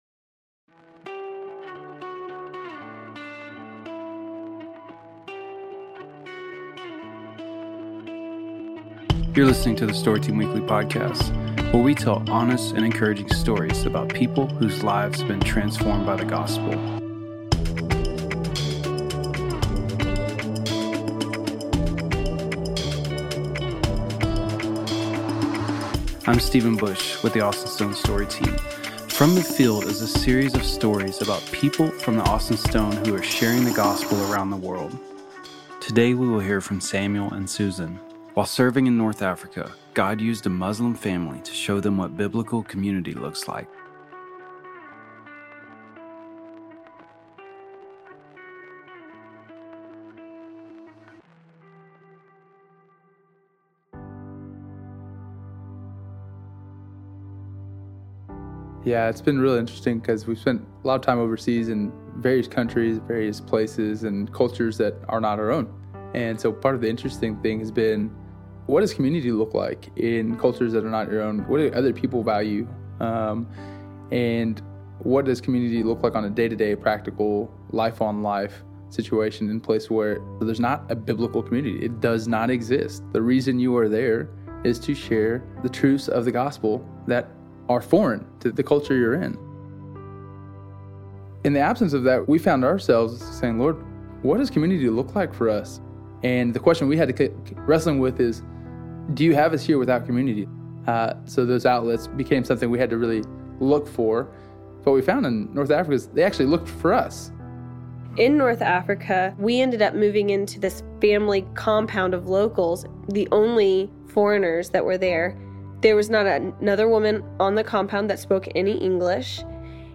Download - Joy (Advent Spoken Word) | Podbean